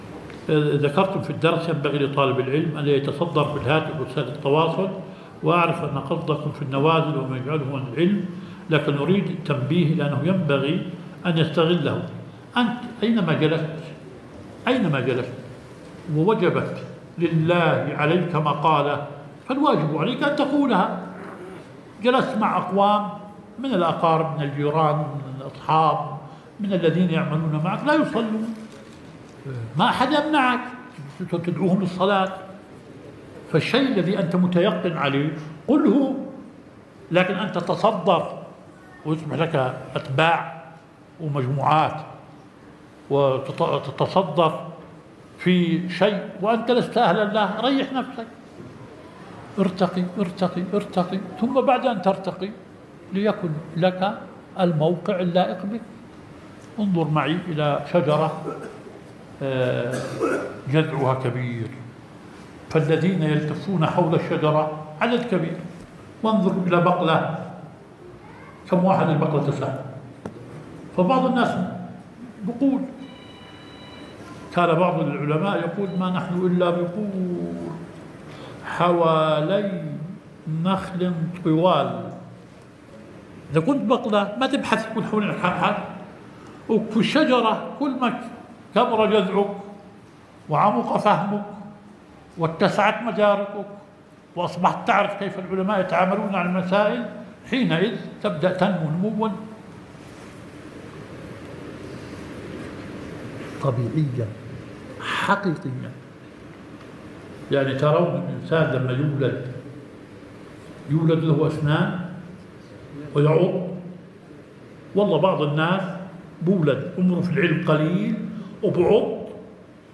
البث المباشر